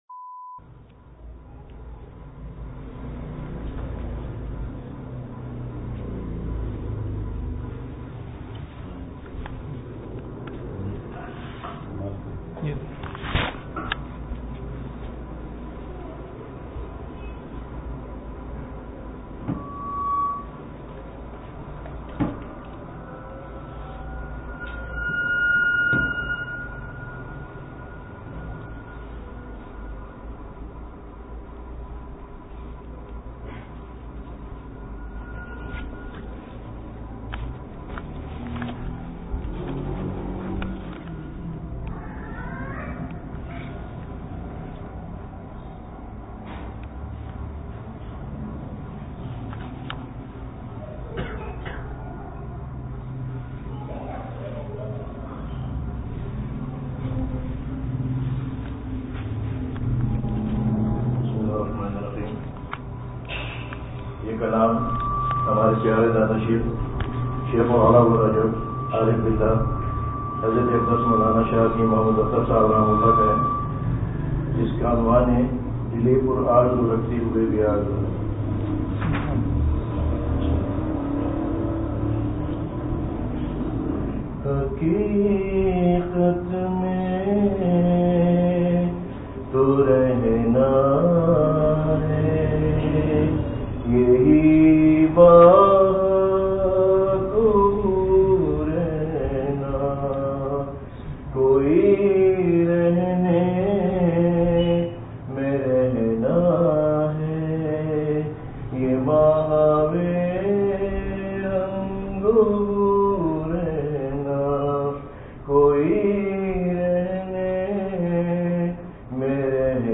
Majlis of December02, 2017